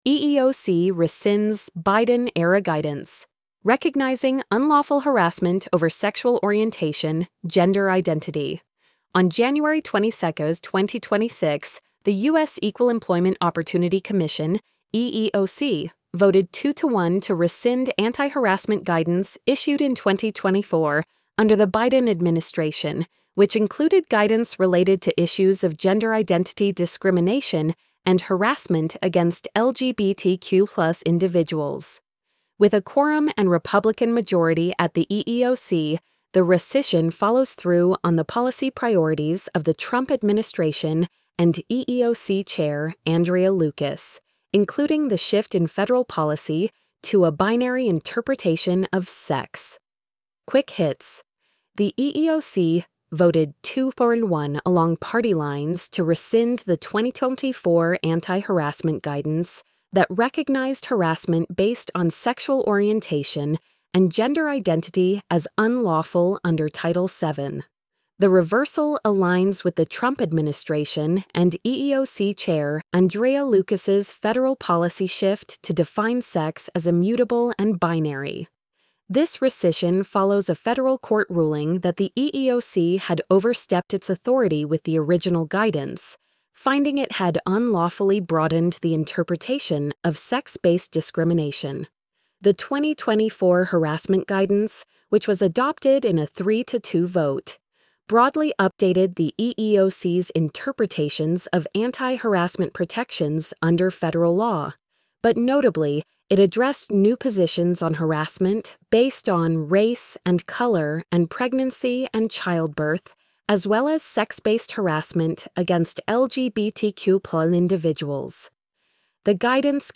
eeoc-rescinds-biden-era-guidance-recognizing-unlawful-harassment-over-sexual-orientation-gender-identity-tts-2.mp3